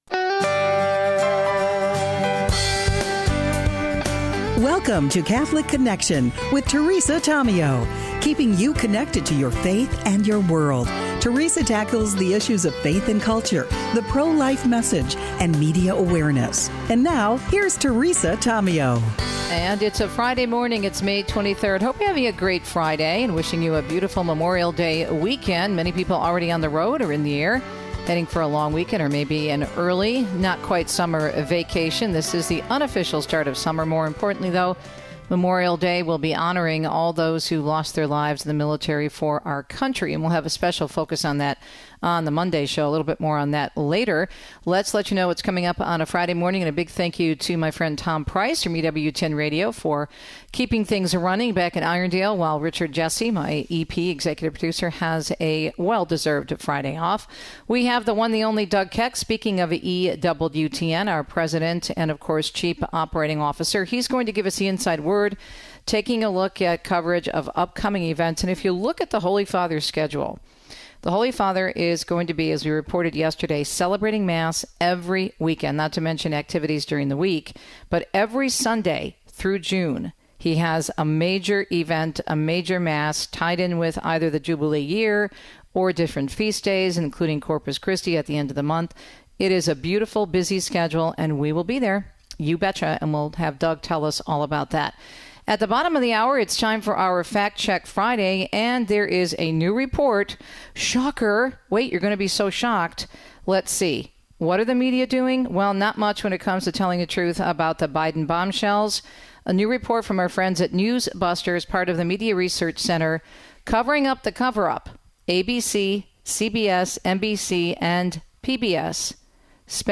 coach four Pump Heads via Zoom. Mind Pump Fit Tip: The 5 MOST effective ways to achieve a calorie deficit.